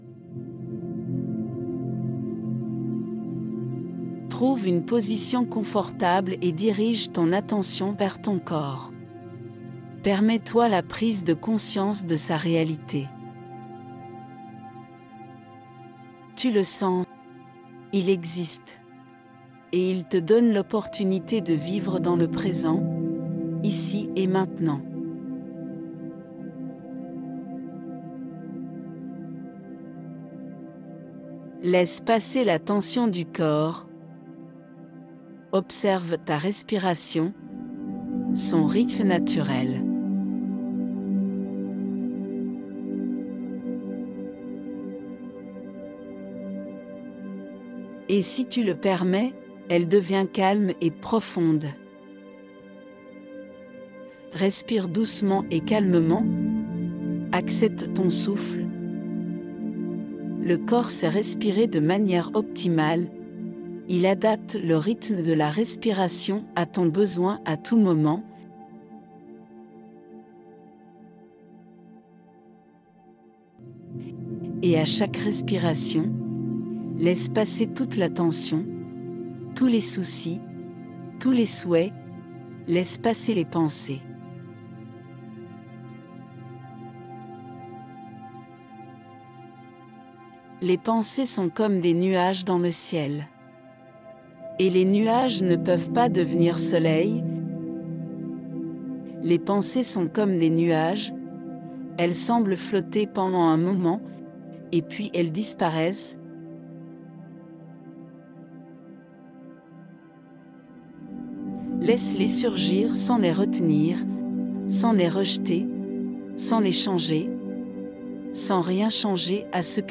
Meditation-du-sourire.wav